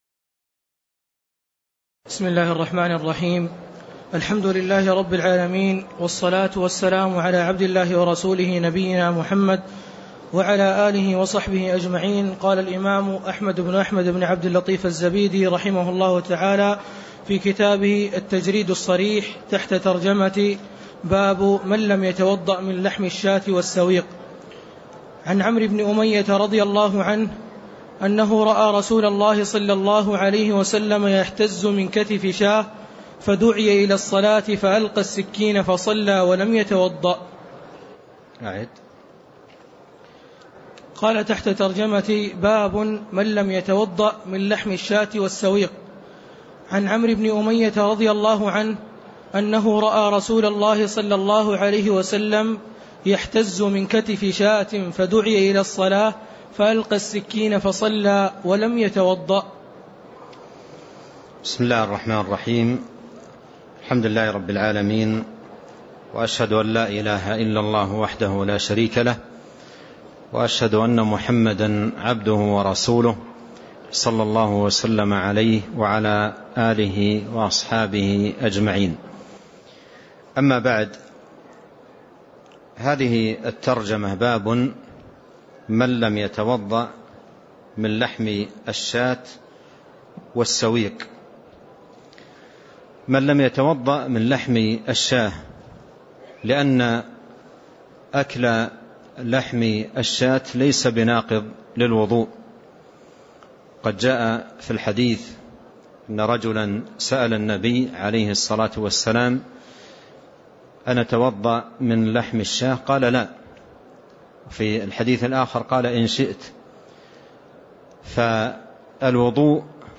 تاريخ النشر ٢٦ جمادى الأولى ١٤٣٣ هـ المكان: المسجد النبوي الشيخ